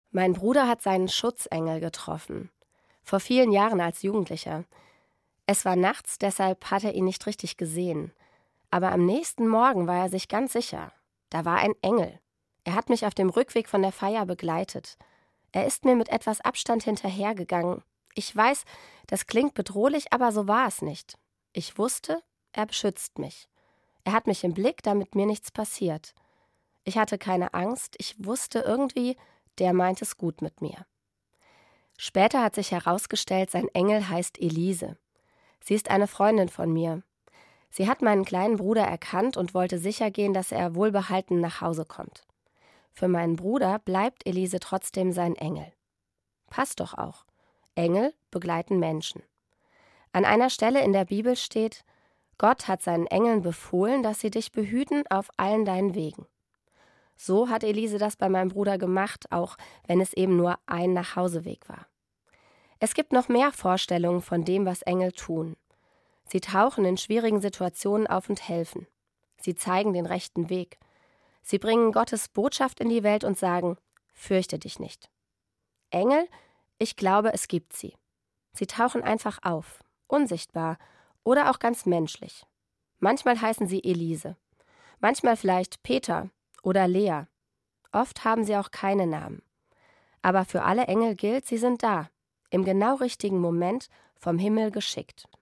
Evangelische Pfarrerin, Herborn